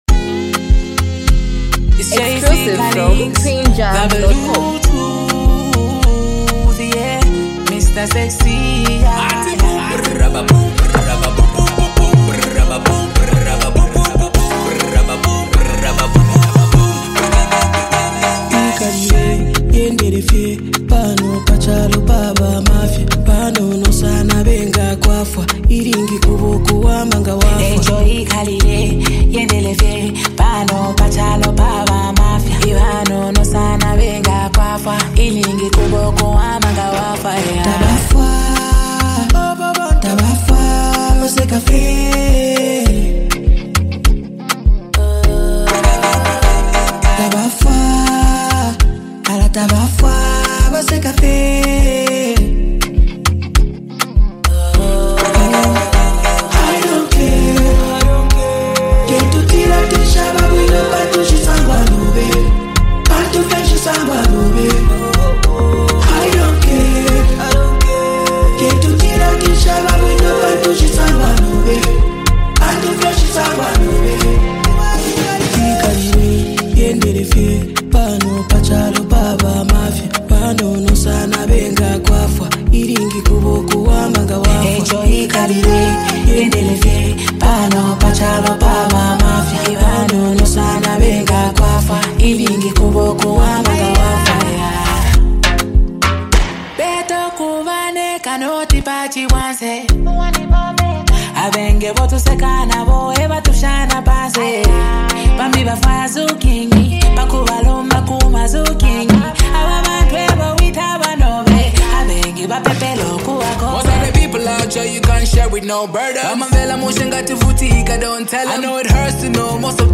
an emotionally rich song
soulful and heartfelt hook